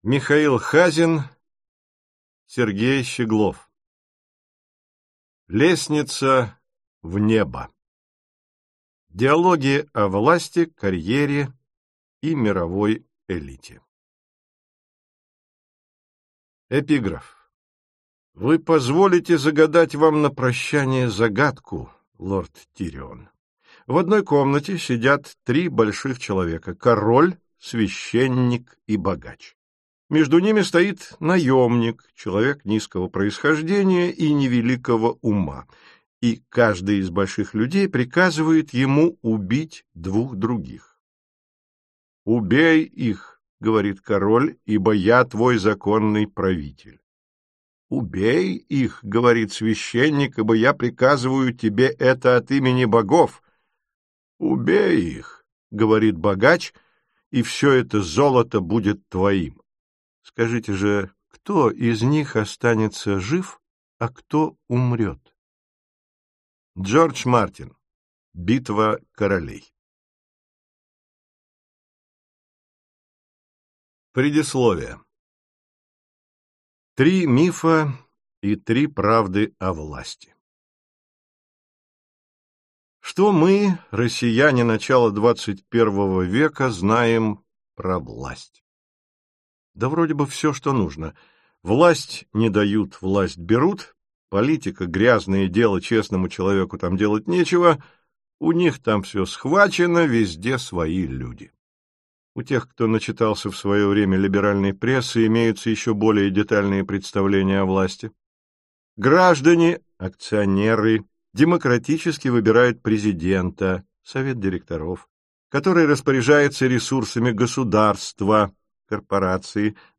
Аудиокнига Лестница в небо. Диалоги о власти, карьере и мировой элите. Часть 1 | Библиотека аудиокниг